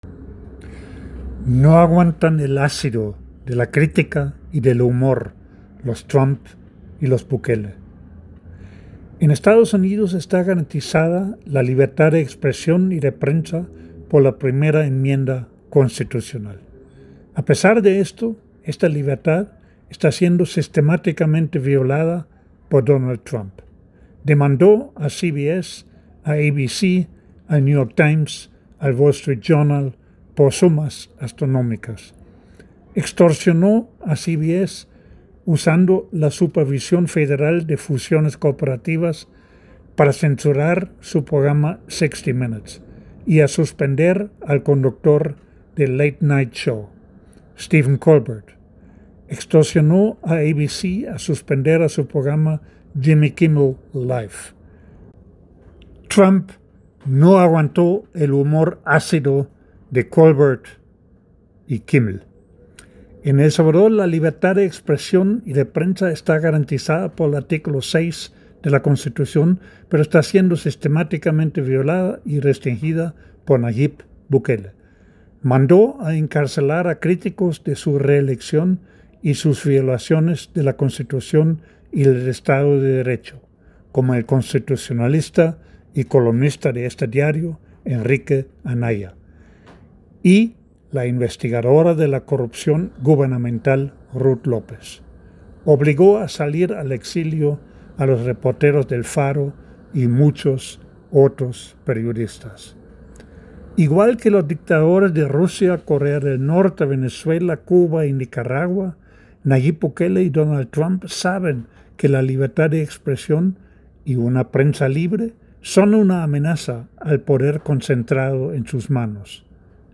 En la voz del autor